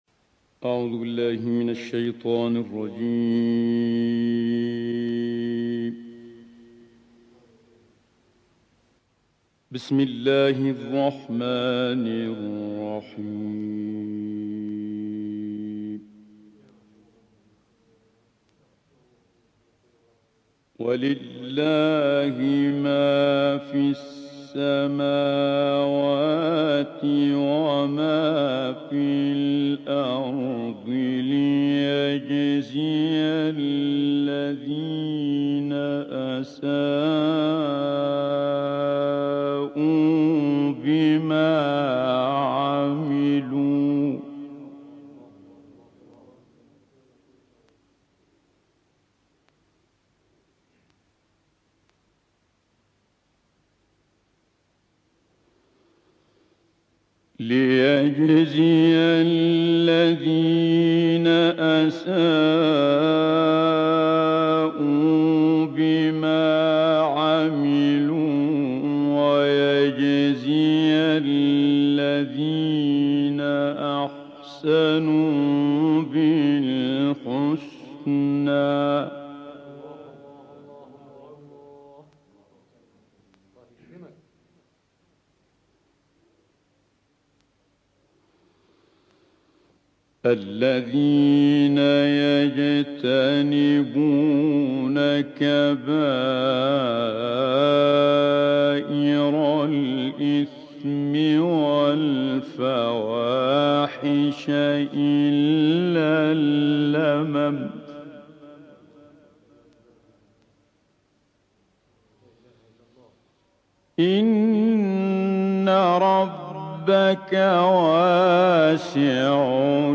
سوره نجم با صدای استاد عبدالعال+ دانلود/ عظمت زندگی در جهان دیگر غیر قابل درک
گروه فعالیت‌های قرآنی: قطعه‌ای از تلاوت استاد سید متولی عبدالعال از آیات ۳1-۶۲ از سوره نجم و آیات ابتدایی سوره قمر ارائه می‌شود.